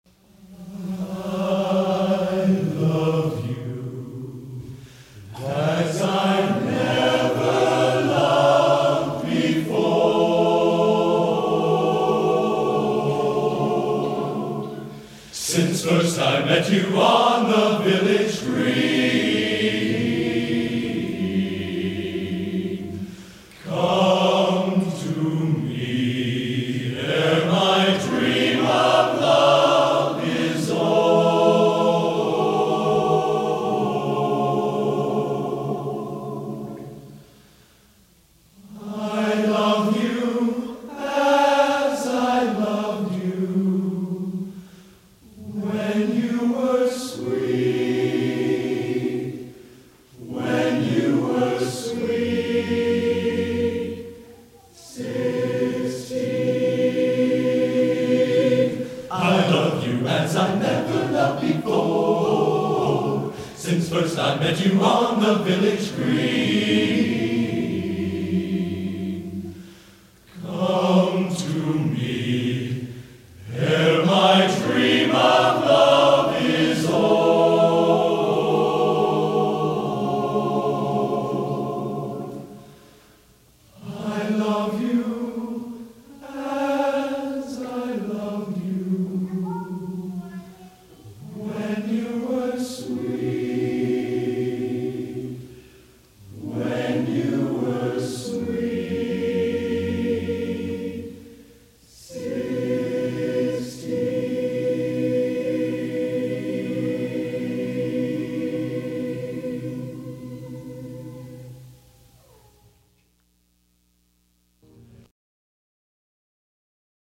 Genre: | Type: Surround / Table